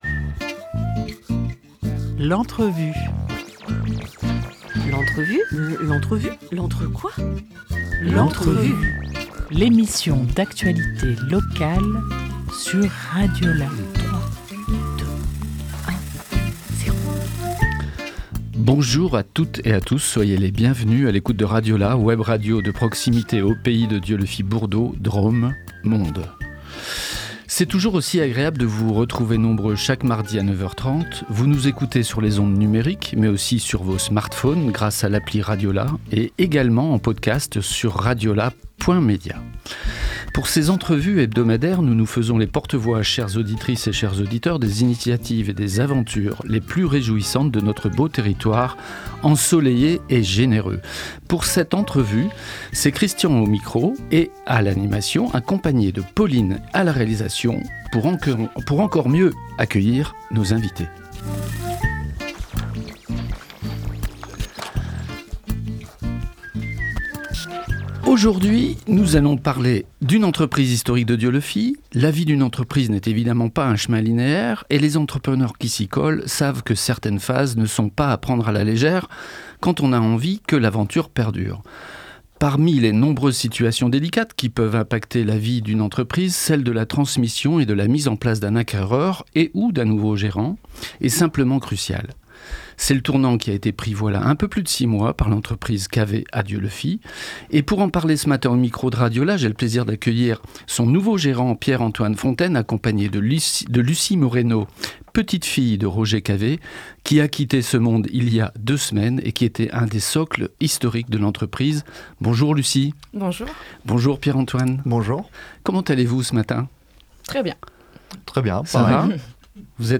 9 décembre 2025 11:12 | Interview